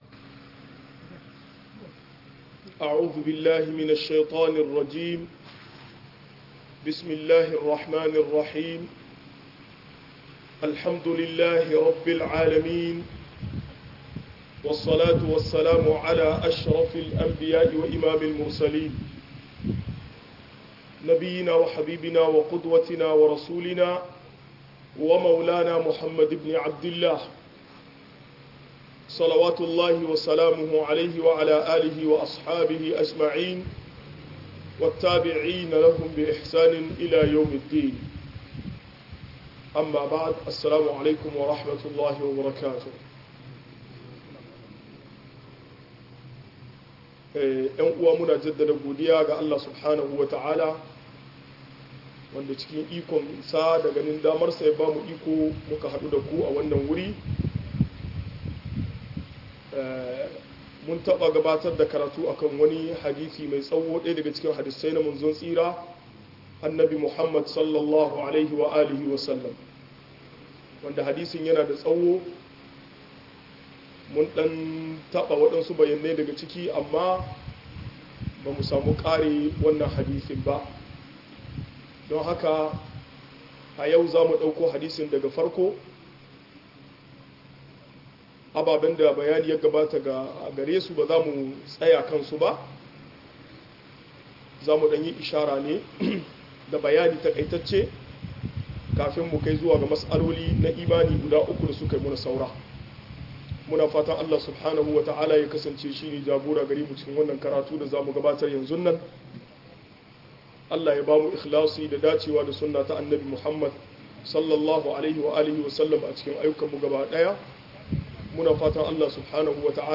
Fa'idodi cikin hadisin jibril - MUHADARA